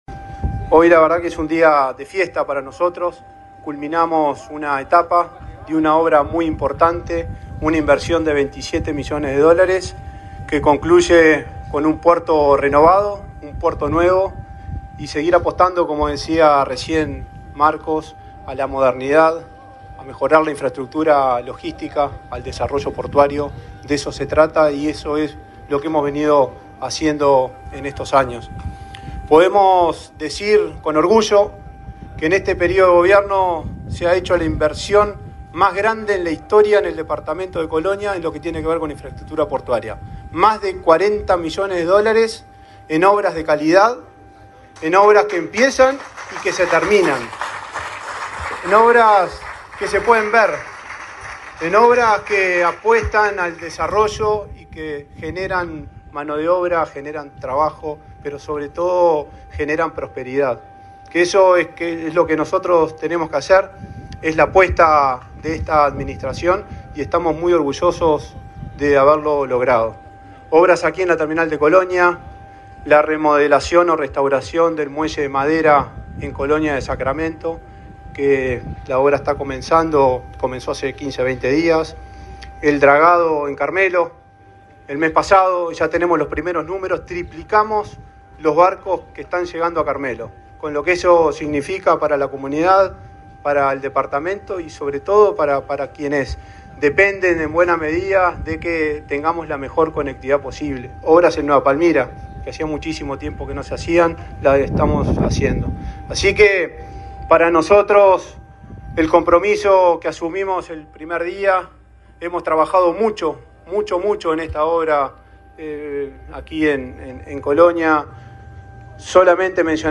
Palabra de autoridades en acto en el puerto de Colonia
Palabra de autoridades en acto en el puerto de Colonia 16/10/2024 Compartir Facebook X Copiar enlace WhatsApp LinkedIn El presidente de la Administración Nacional de Puertos (ANP), Juan Curbelo; el ministro de Transporte, José Luis Falero, y su par de Turismo, Eduardo Sanguinetti, participaron del acto de inauguración de las nuevas obras en la terminal de pasajeros del puerto de Colonia.